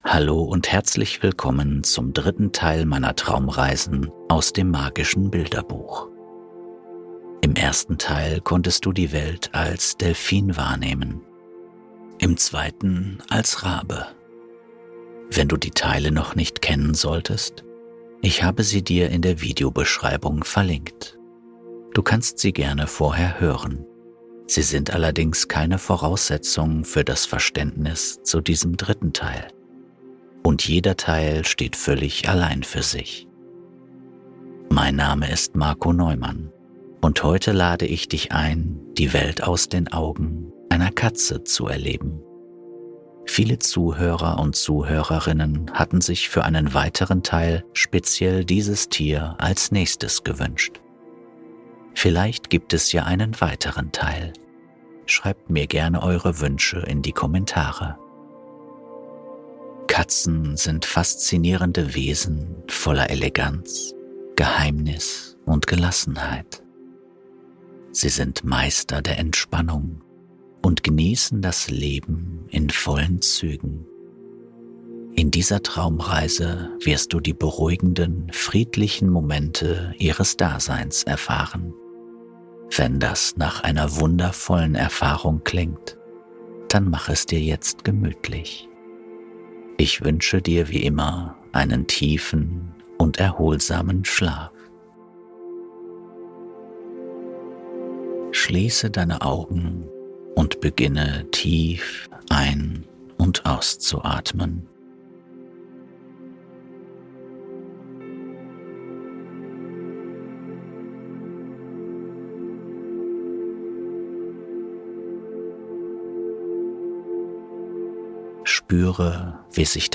Goldener Herbst-HACK: Von der Hütte direkt ins Traumland (+ Regen)!